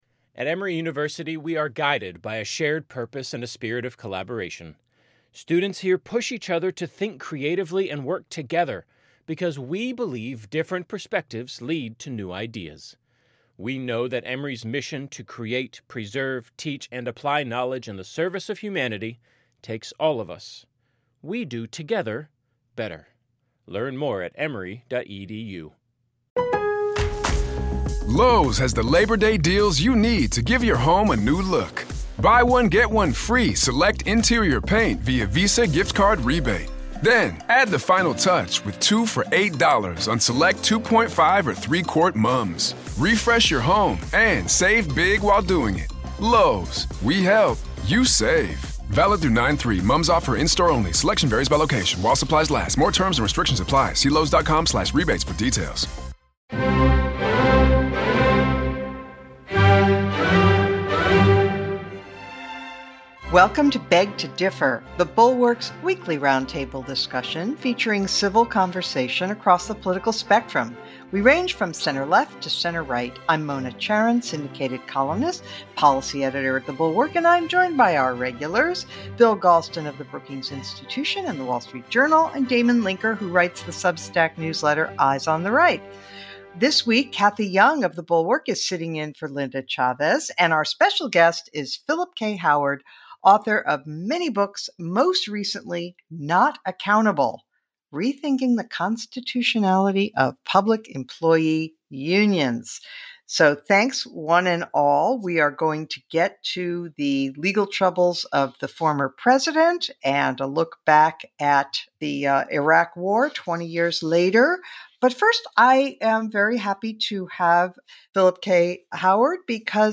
Philip K. Howard joins the group to discuss his book on public sector unions. The panel — with special guest Cathy Young — also addresses Trump's possible indictment and looks back at the 20th anniversary of the start of the Iraq War.